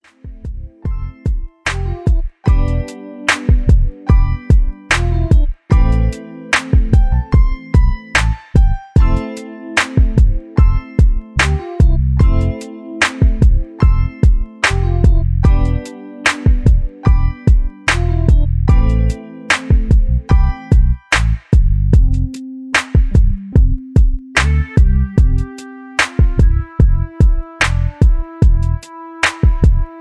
Mid Tempo RnB Beat